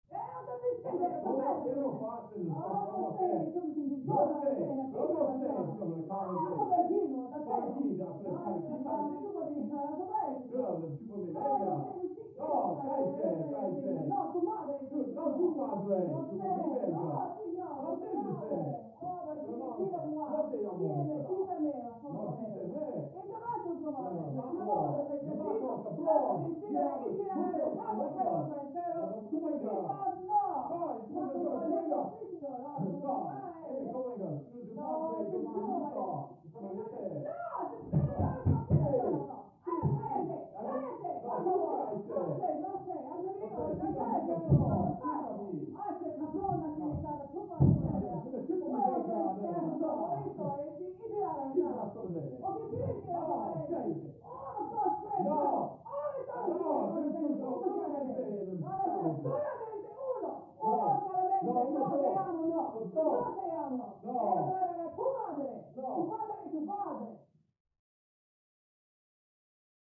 Argue; Could Be Spanish-italian Couple Yelling At Each Other, Through Thick Wall.